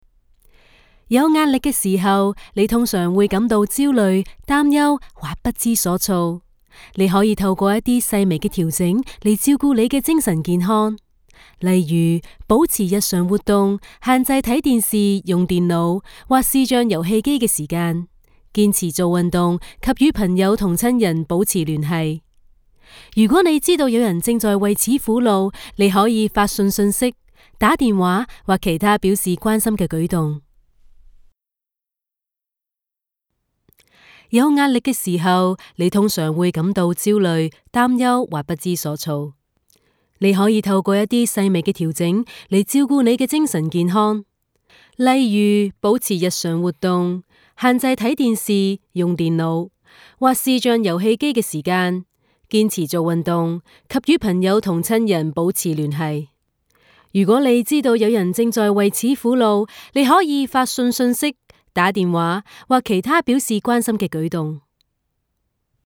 Female
20s, 30s, 40s
Approachable, Conversational, Friendly, Natural, Warm
Voice reels
Microphone: blue microphones yeti